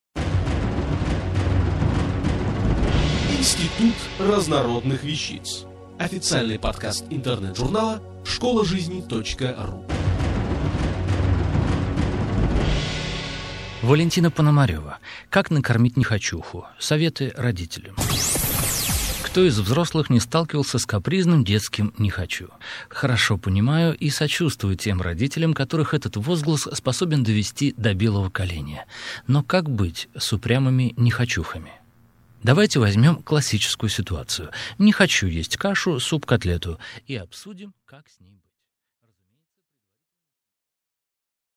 Аудиокнига Как накормить «нехочуху»? Советы родителям | Библиотека аудиокниг